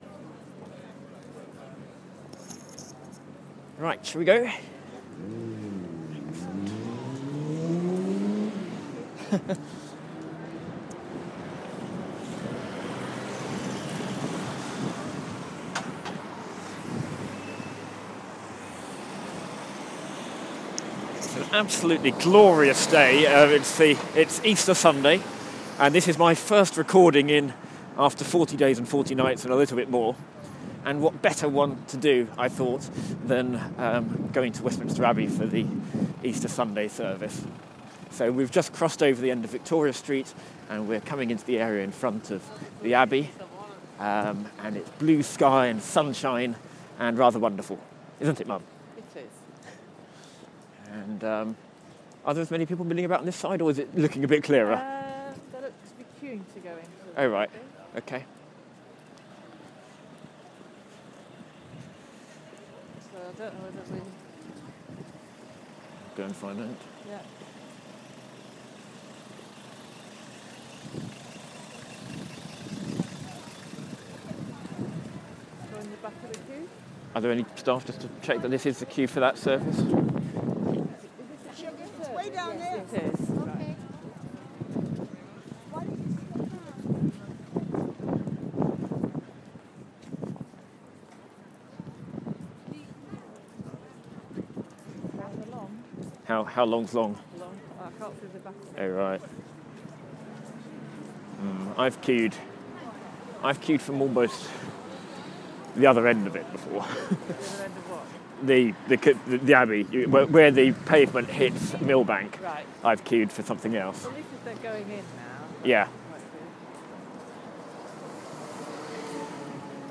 Ending forty days and forty nights of Lenten Audioboo abstinence I record some sounds from the Easter morning service at Westminster Abbey.